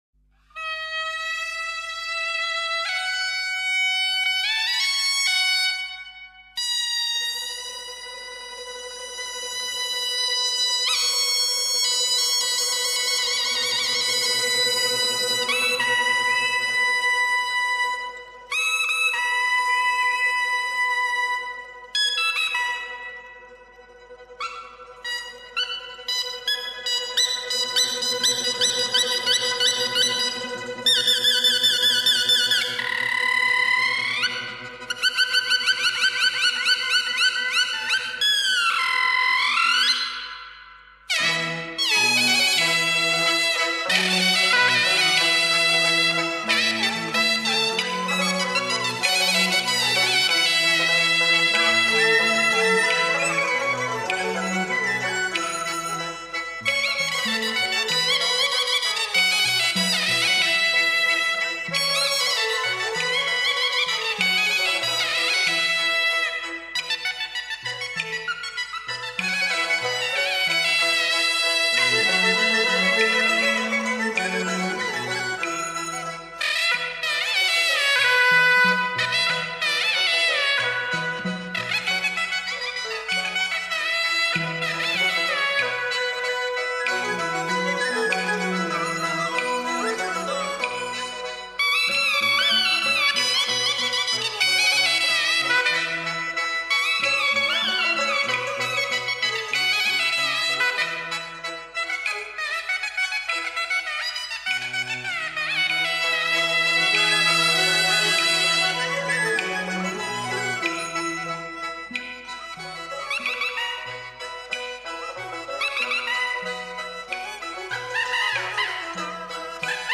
让我们静静聆听这悠扬如诗般的清新天籁之音，感受纯净舒适的民族音乐的魅力！
唢呐